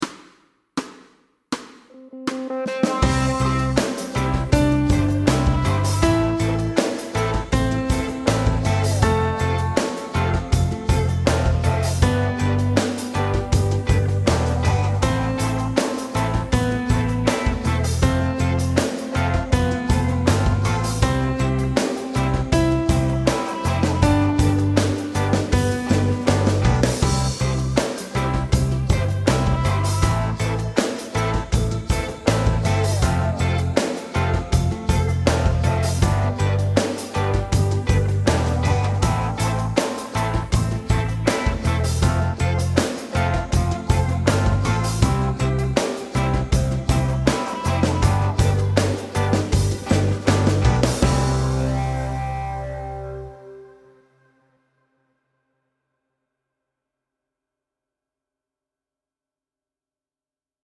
Fast C instr (demo)